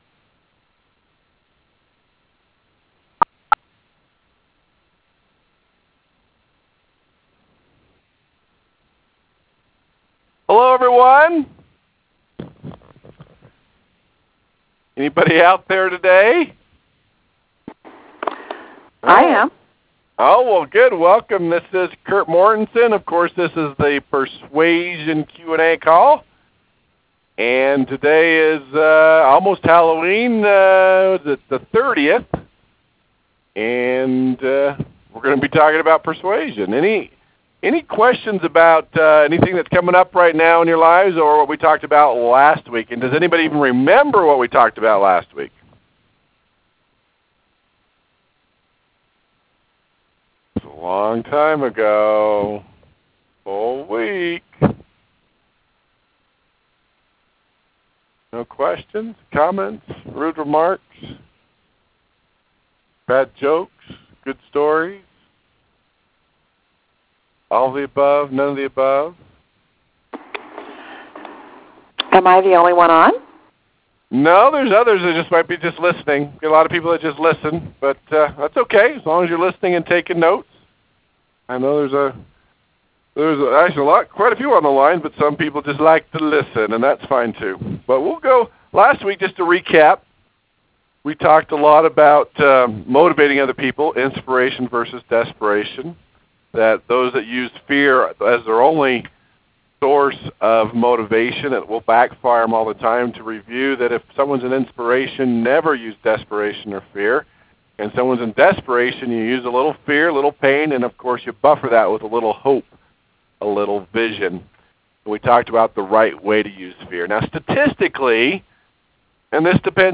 ‹ Ego and Esteem FITD › Posted in Conference Calls